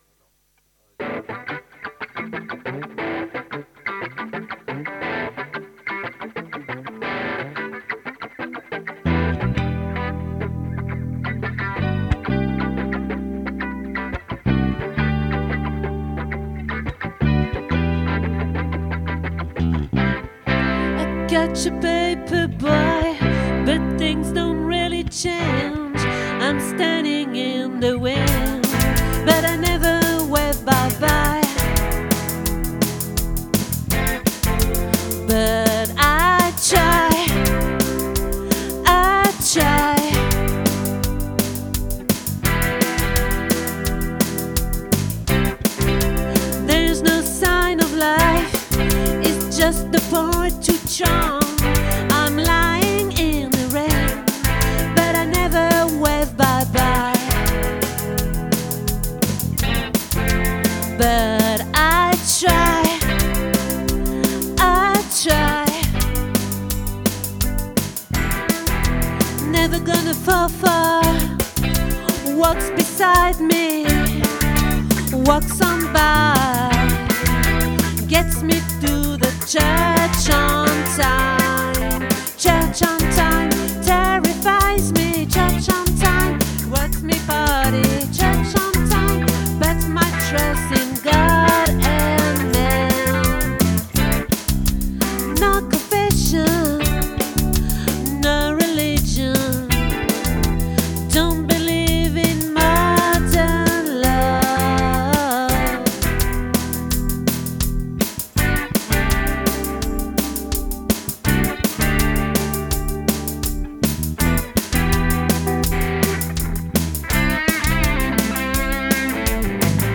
🏠 Accueil Repetitions Records_2025_02_03